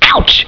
ouch.wav